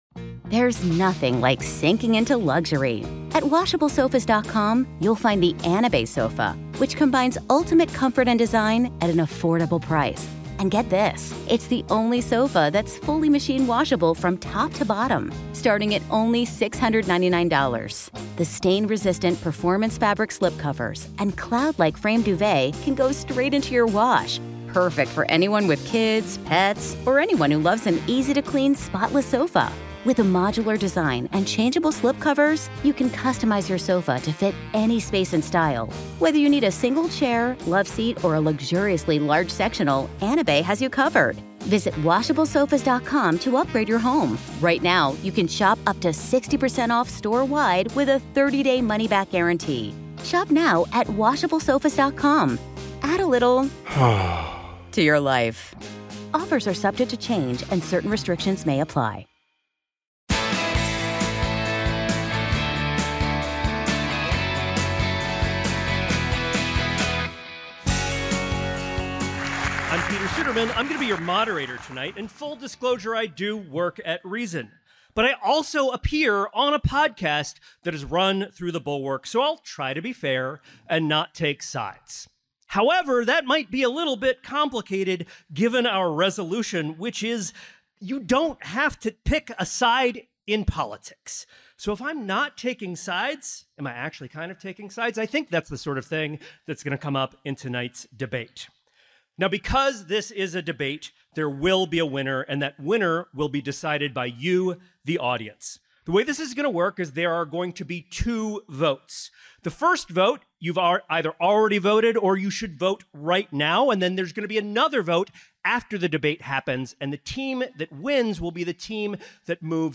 The debate was sharp, occasionally heated, enlightening, and definitely amusing.